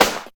50 SNARE.wav